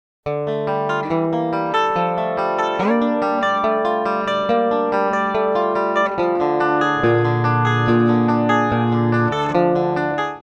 Диайки для сравнения. Elixir Optiweb 10-52. В первом примере струны практически новые (строй drop D).